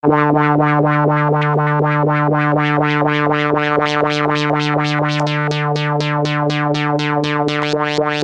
Free MP3 vintage Korg PS3100 loops & sound effects 7